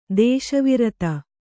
♪ dēśa virata